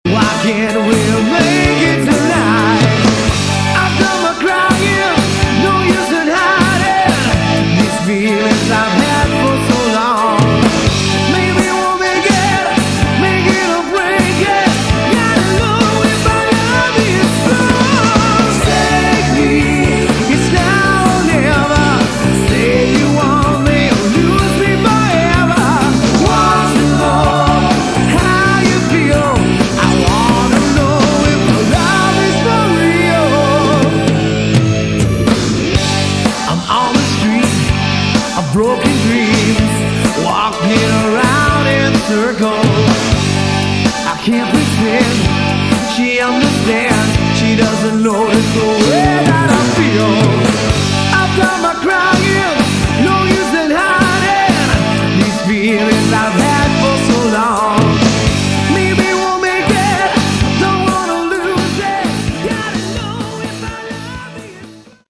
Category: AOR / Melodic Rock
lead vocals, keyboards, rhythm guitar
bass guitar, backing vocals
drums, percussion
lead guitar, spanish guitar, backing vocals